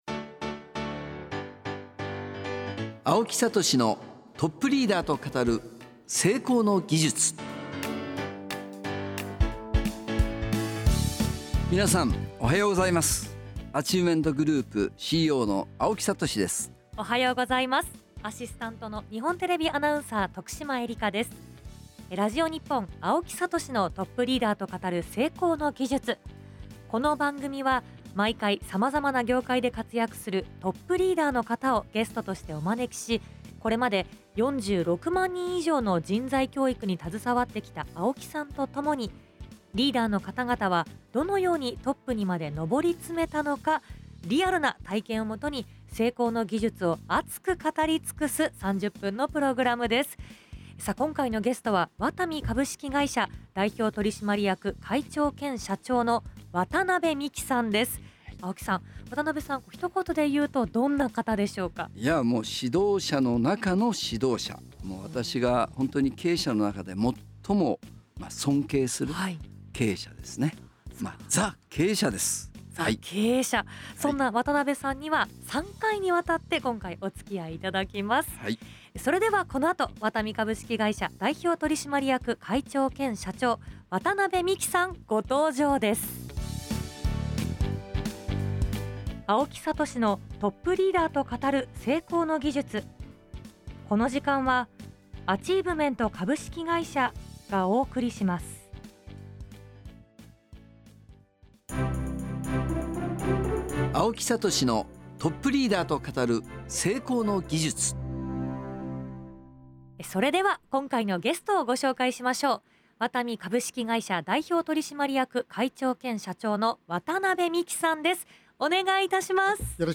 ▼第17回放送音声はこちら▼3月24日(金)あさ6:00~6:30今週のゲストは私が人としても経営者としても尊敬しているワタミ株式会社代表取締役会長 兼 社長渡邉美樹さんです。
ゲスト:ワタミ株式会社 代表取締役会長 兼 社長 渡邉美樹様[前編]